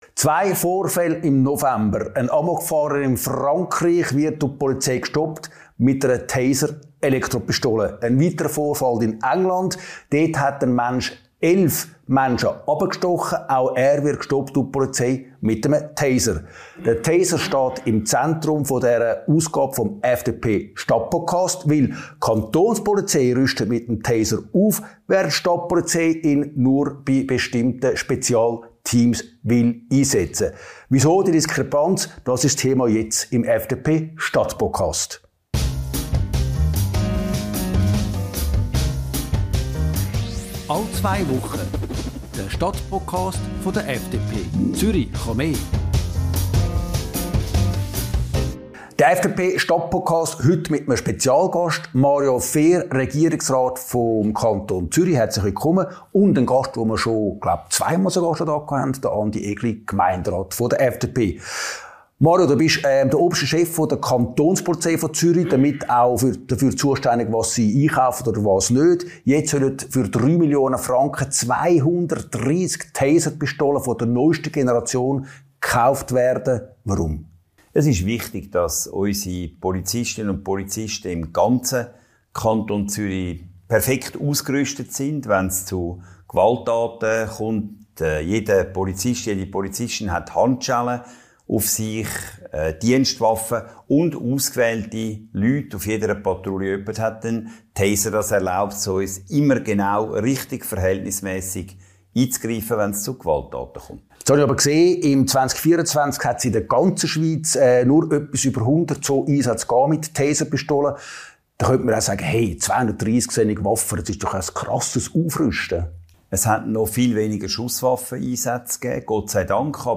Das Doppelinterview im Stadtpodcast.